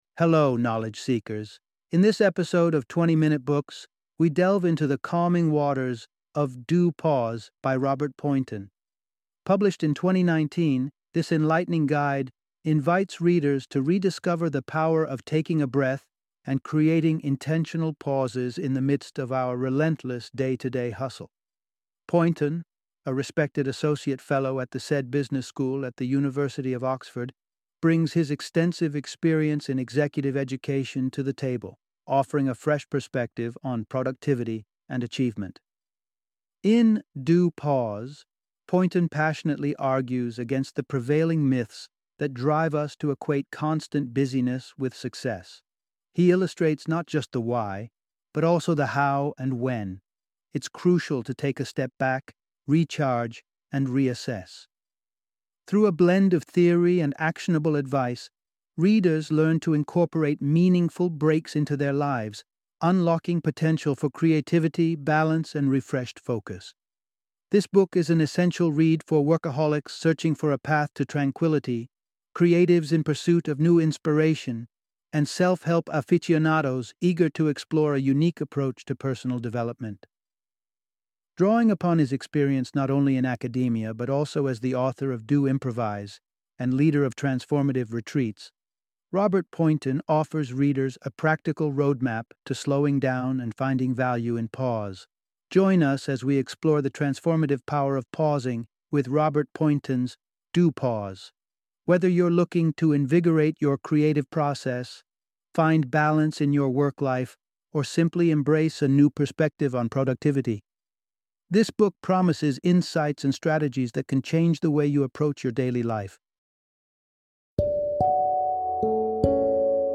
Do Pause - Audiobook Summary